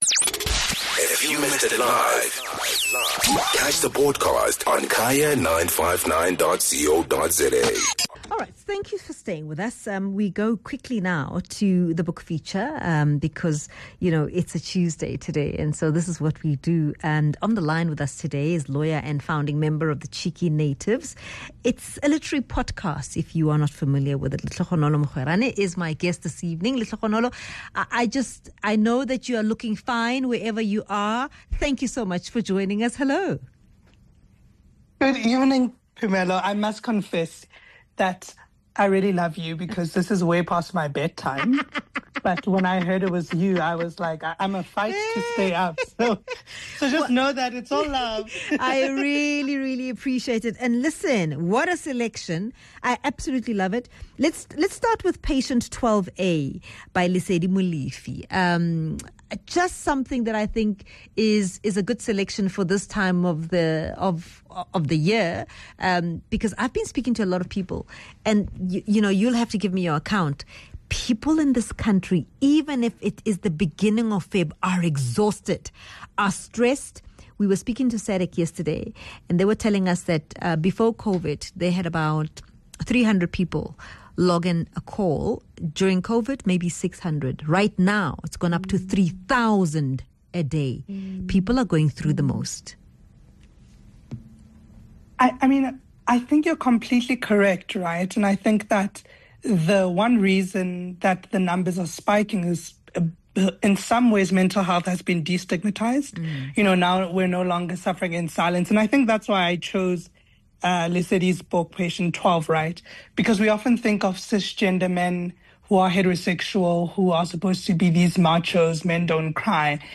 4 Feb POV Book Review